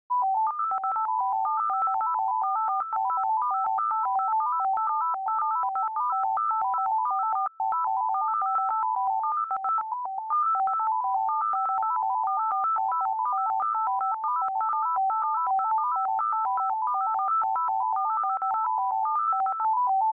Starting in the early sixties, the composer Steve Reich made famous a technique called 'phasing' in which two loops, almost identical but of slightly different lengths, are played simultaneously so that you hear the contents of the loop juxtaposed against itself at all possible phases. This assignment is to make a simple phased-loop player using sinusoidal oscillators with wavetables to control their pitches.